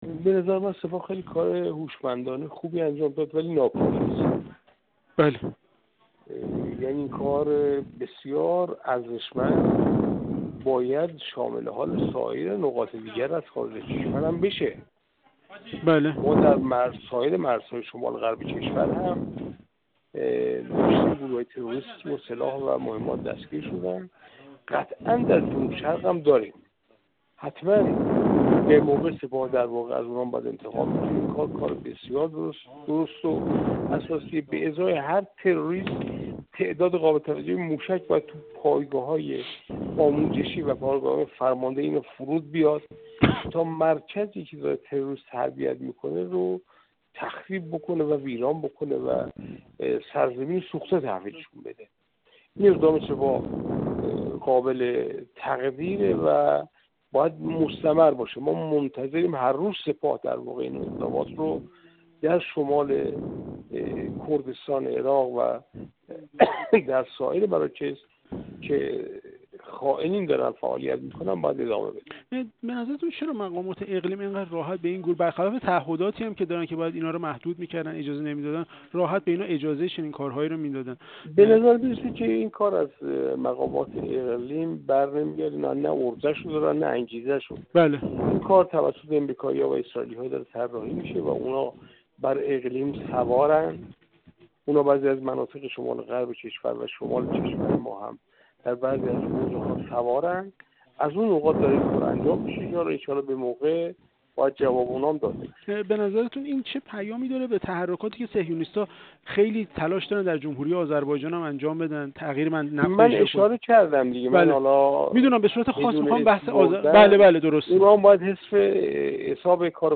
منصور حقیقت‌پور، کارشناس مسائل سیاسی و نایب رئیس پیشین کمیسیون امنیت ملی و سیاست خارجی مجلس، در گفت‌وگو با ایکنا درباره چندین مرحله عملیات سپاه علیه گروهک‌های تروریستی و تجزیه‌طلب در شمال اقلیم کردستان عراق گفت: حملات سپاه اقدامی هوشمندانه اما ناکافی است.